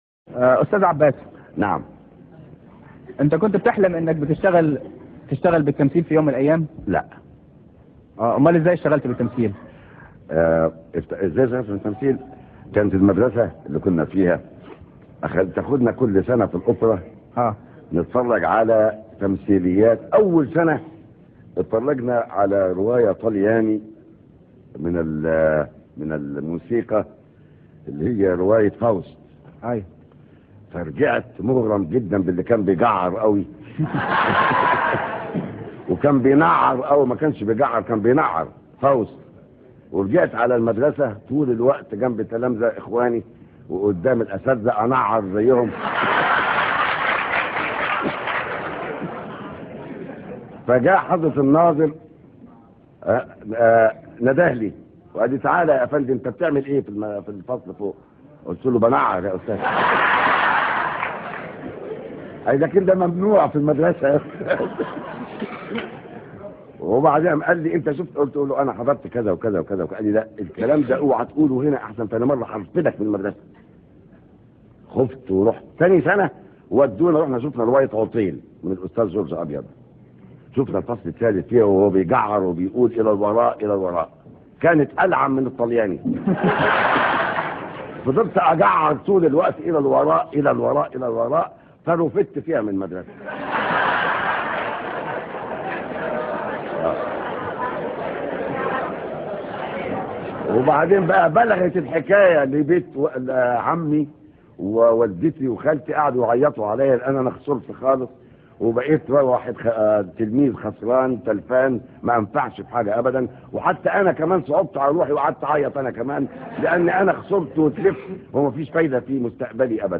(1) Un acteur égyptien (عباس فارس) évoquait, dans un entretien très drôle, l'étrangeté de cela pour l'oreille d'un oriental :